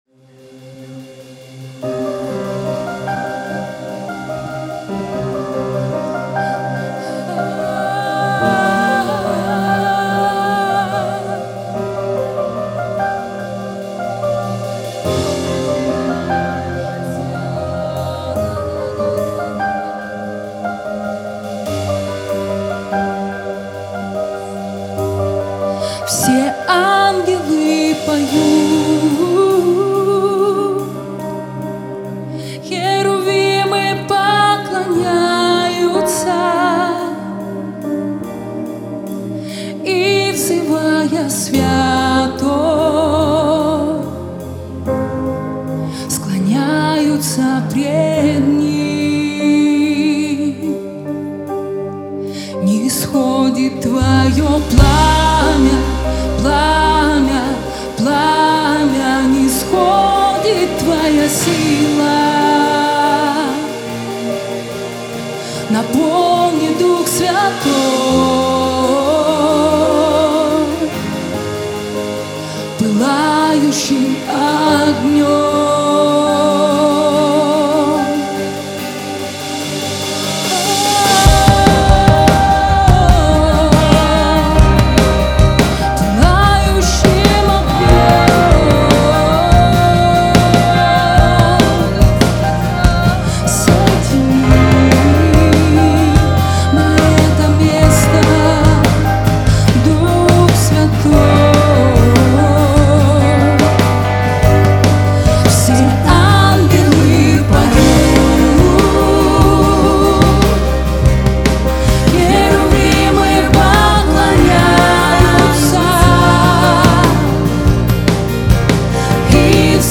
795 просмотров 1870 прослушиваний 230 скачиваний BPM: 145